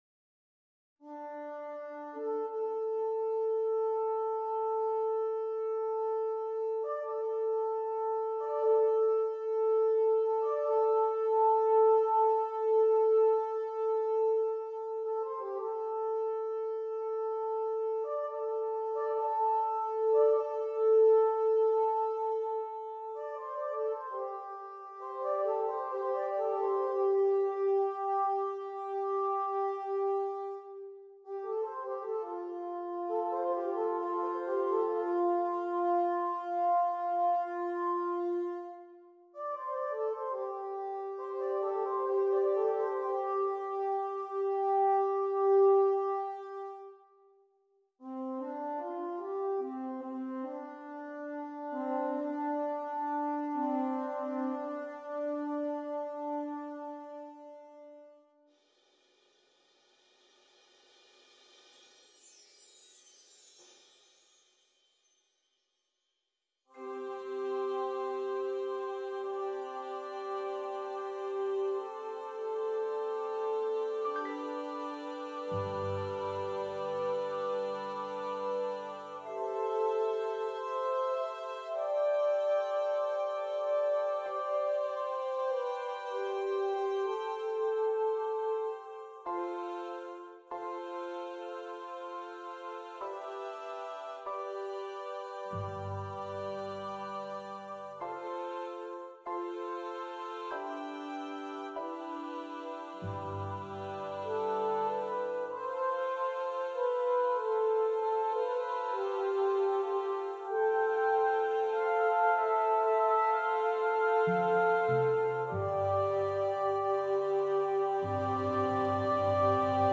10 Trumpets and Percussion